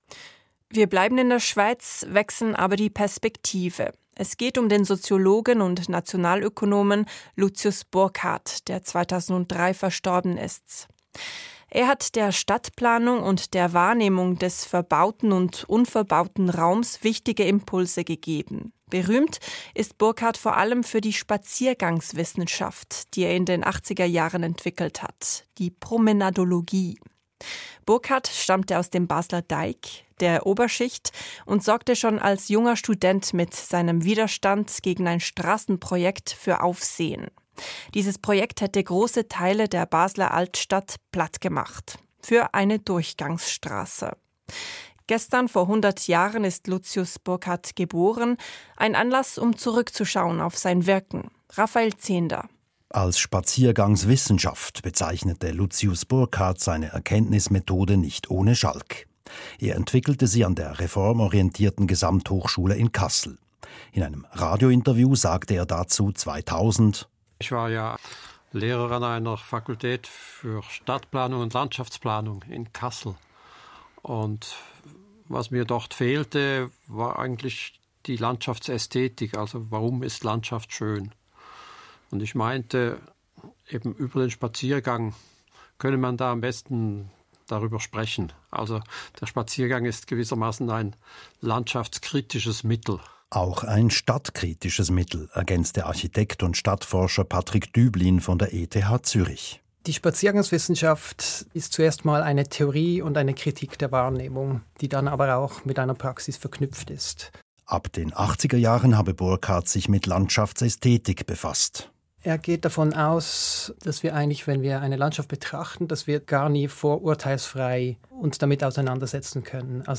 In January, Swiss Radio (SRF2 Kultur) invited me to discuss the relevance of the work of Lucius and Annemarie Burckhardt. Listen to the interview (in German) here .